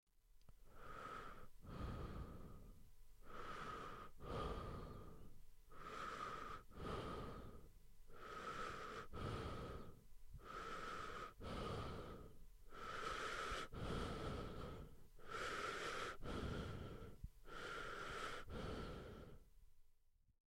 呼吸的女人
描述：一个女人呼吸的录音
标签： 呼气 呼气 吸气 女人
声道立体声